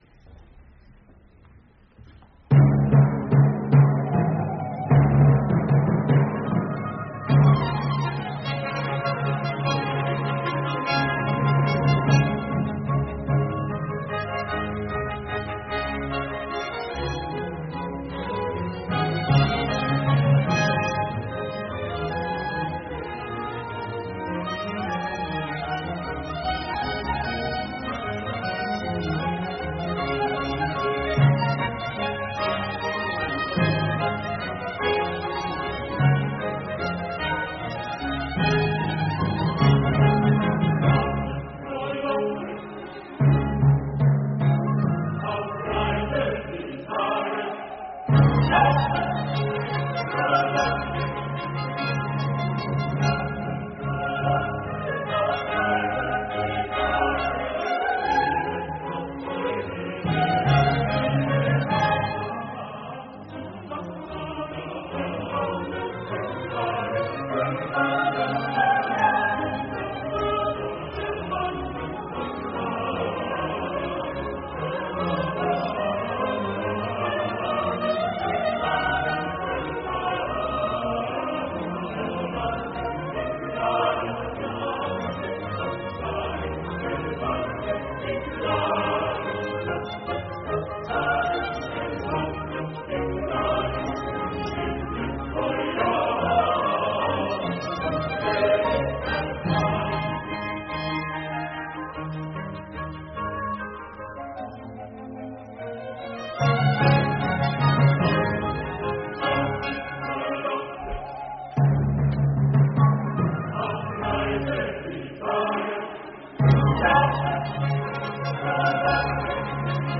Netherlands Chamber Choir , Les Talens Lyriques
soprano
tenor
sota la direcció de Christophe Rousset . Part I . Feria 1 Nativitatis Christi (Dia de Nadal): descriu el naixement de Jesús.
bachchristmasoratorioarnhem2022.mp3